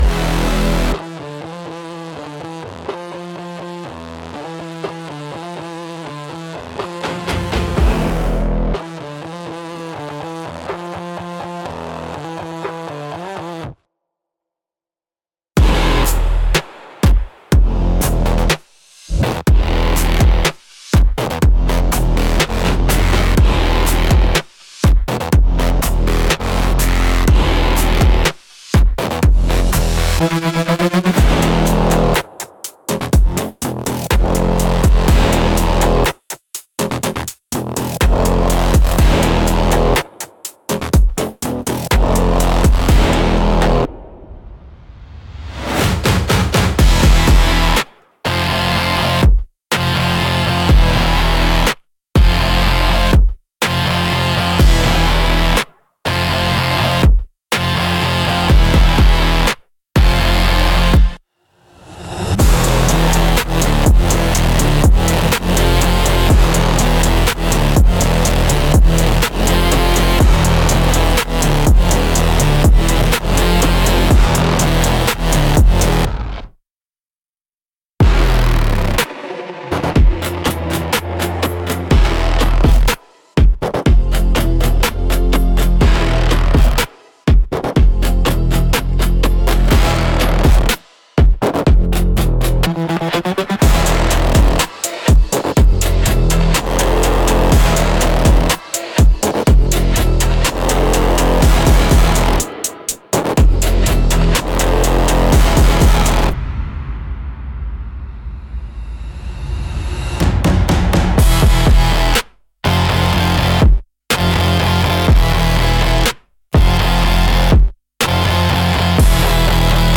Instrumental - Cinematic Burst x Future Hip-Hop